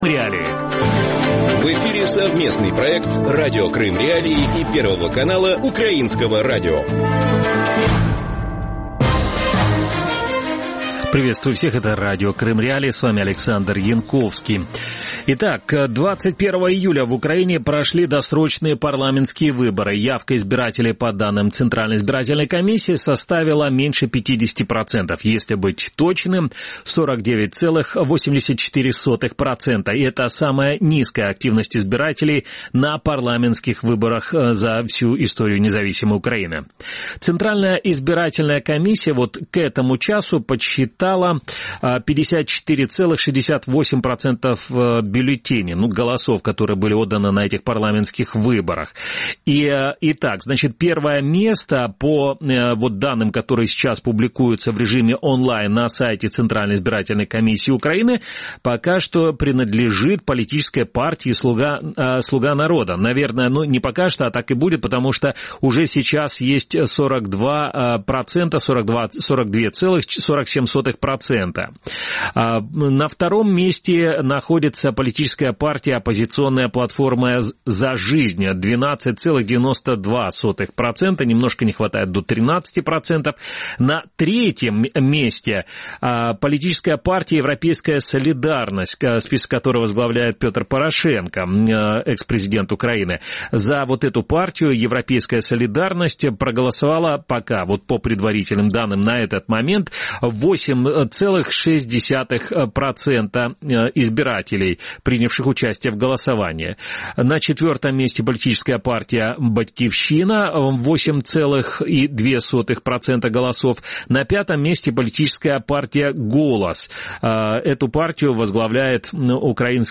Кто из крымчан будет депутатом Верховной Рады 9 созыва? Какие законопроекты, касающиеся Крыма, уже есть в их портфелях? И как голосовали крымчане на этих парламентских выборах? Гости эфира